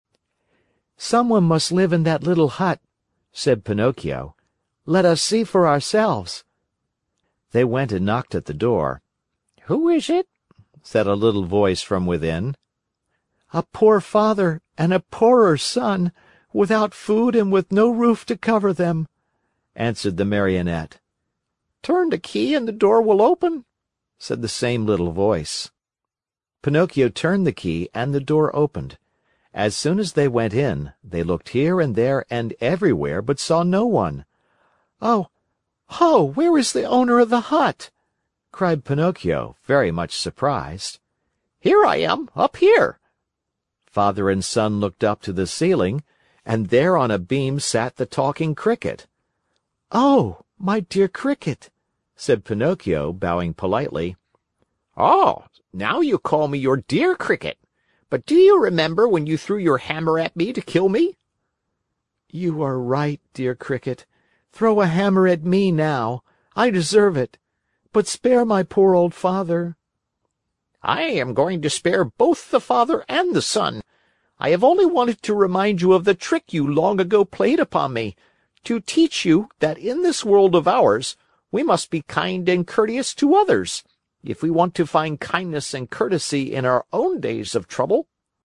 在线英语听力室木偶奇遇记 第159期:匹诺曹梦想成真(5)的听力文件下载,《木偶奇遇记》是双语童话故事的有声读物，包含中英字幕以及英语听力MP3,是听故事学英语的极好素材。